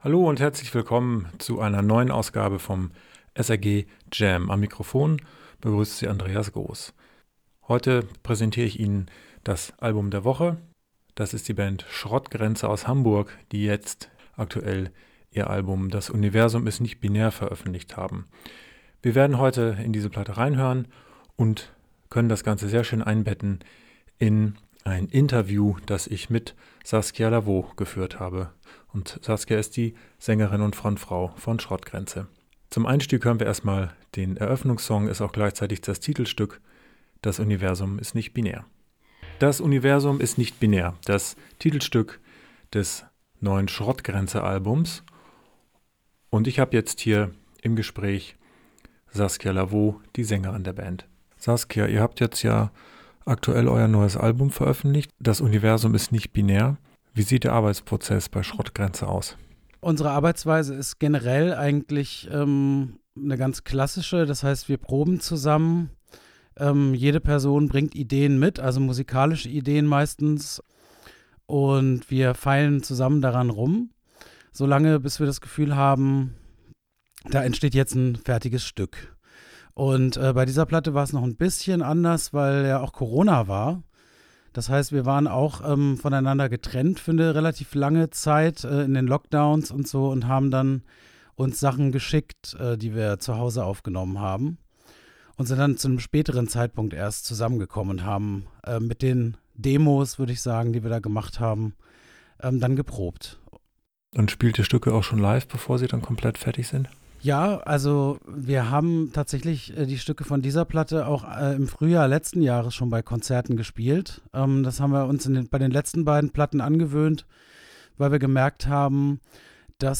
schrottgrenze_interview_stadtradio.mp3